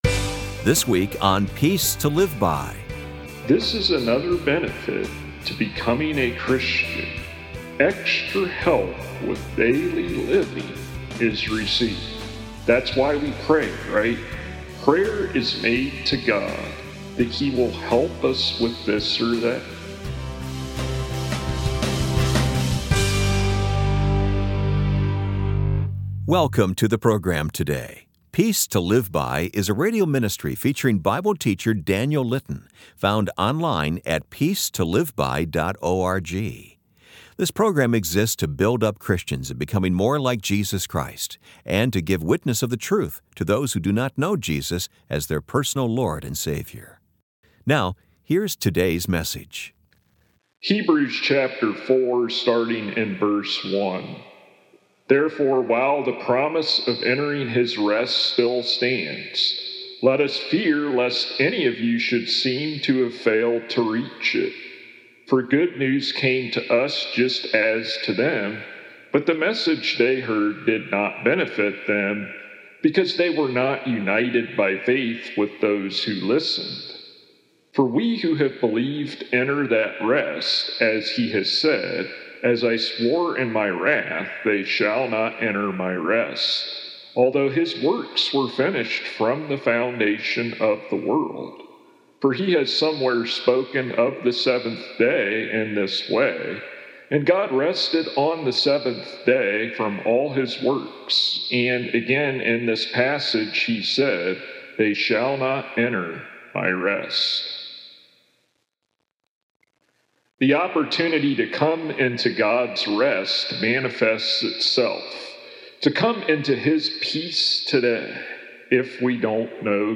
For full sermons without edits for time, tap here to go to downloads page.